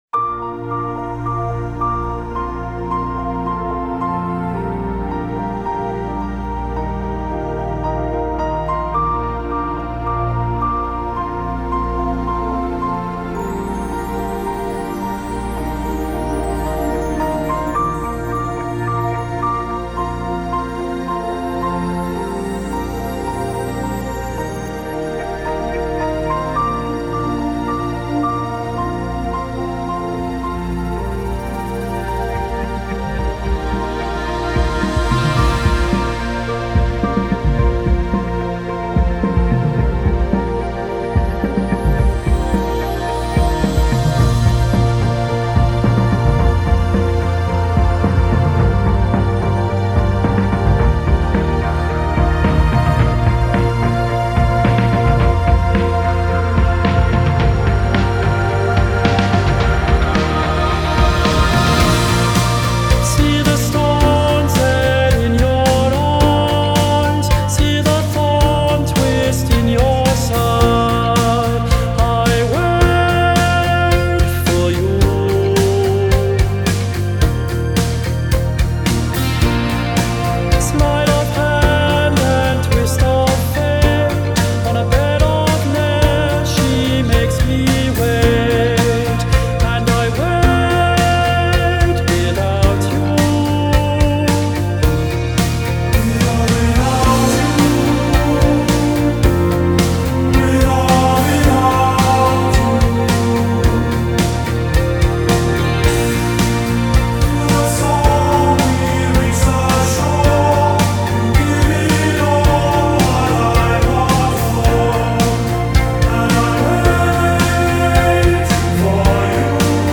Genre: Pop, Chants